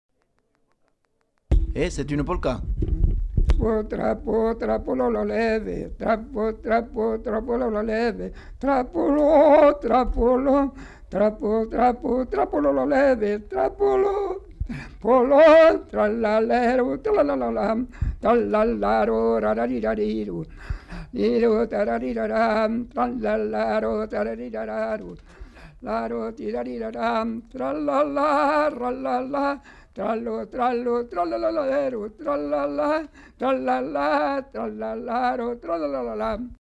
Aire culturelle : Savès
Lieu : Gers
Genre : chant
Effectif : 1
Type de voix : voix d'homme
Production du son : chanté ; fredonné
Danse : polka